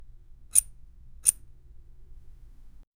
Royalty-free interface sound effects